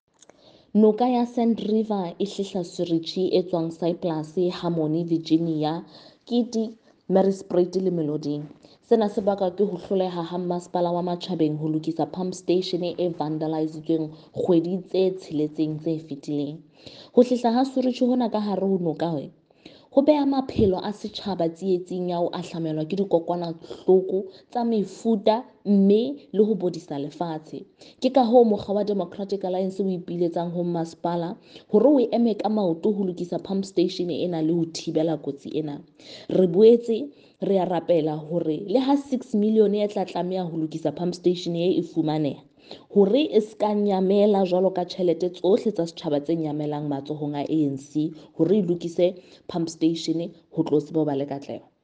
Sesotho by Karabo Khakhau MP.
Sotho-voice-Karabo-Khakhau-3.mp3